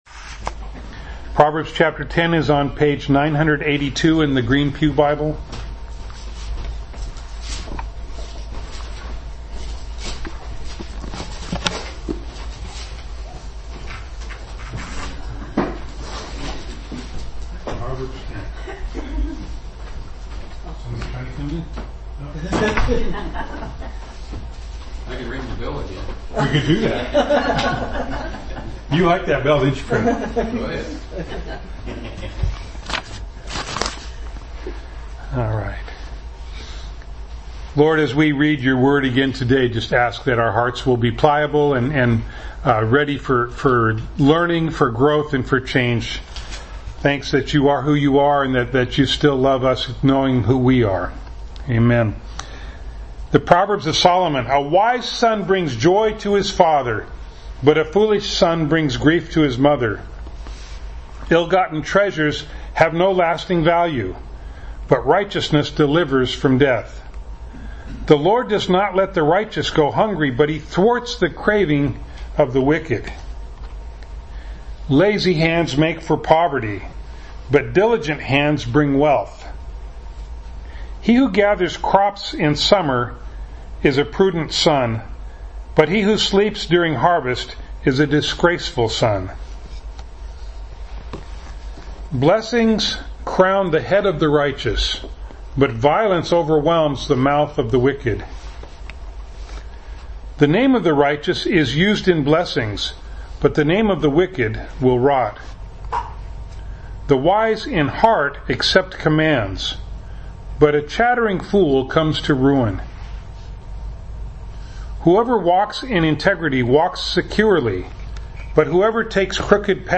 James 2:12 Service Type: Sunday Morning Bible Text